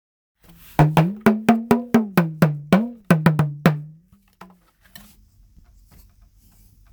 トーキングドラムS
(セネガルではタマ、ナイジェリアではドゥンドゥン、ガーナではドンド)砂時計型の両面太鼓。脇に抱えロープのテンションを変化させ、音程に高低をつけ演奏します。先が曲がった木のスティックを使用。いわゆる「話す太鼓」は、通信手段としても用いられました。
素材： 木 皮